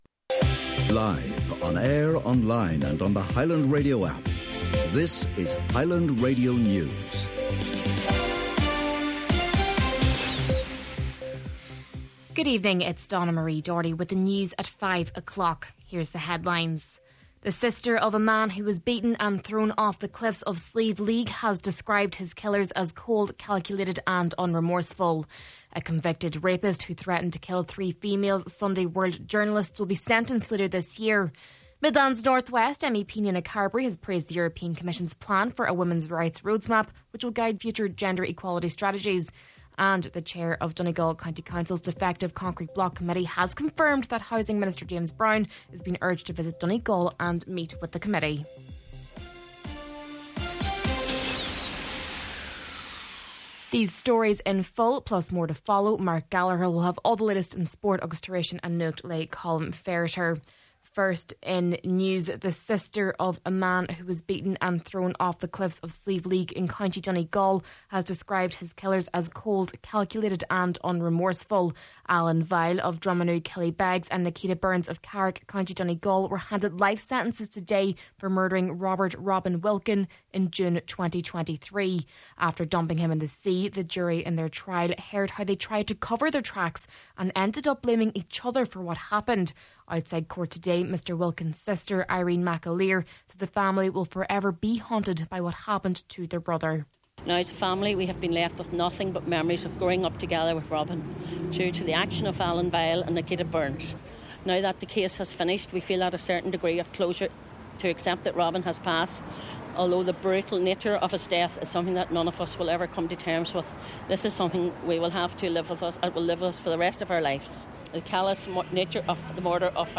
Main Evening News, Sport, An Nuacht and Obituaries – Friday, March 7th